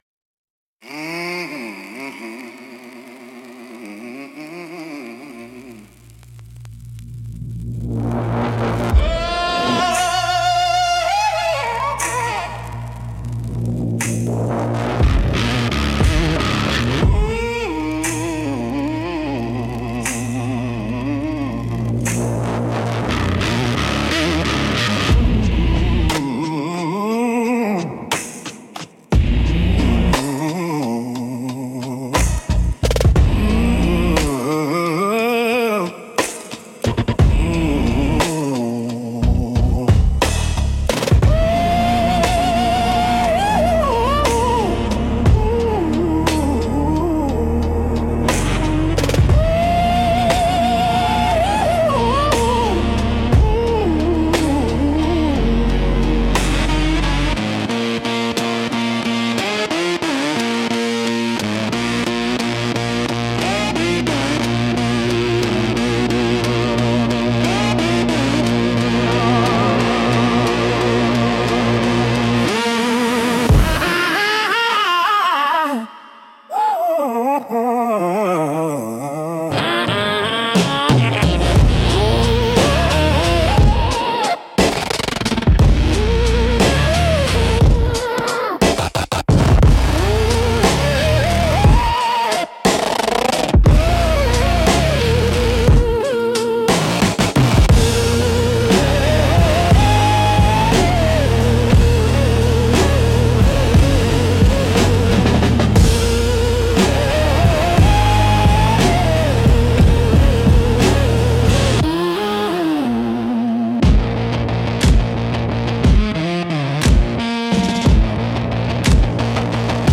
Instrumental - Surveillance Blues - 2.30